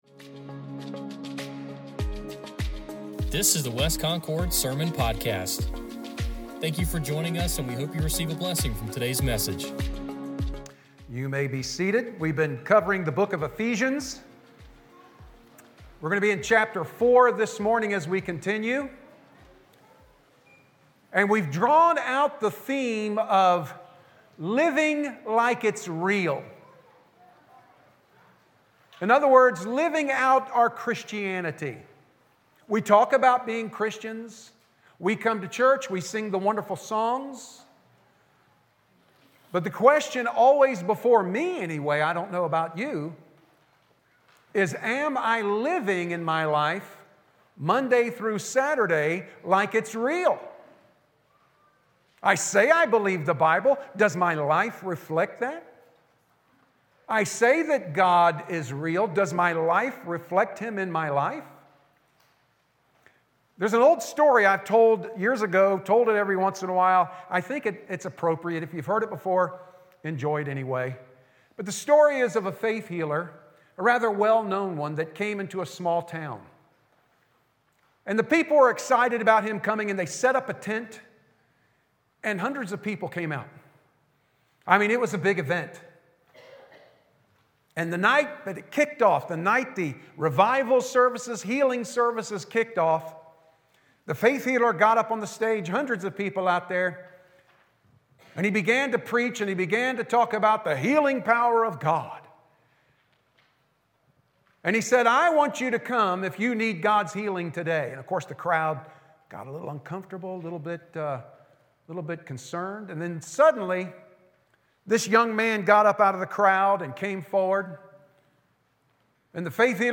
Sermon Podcast | West Concord Baptist Church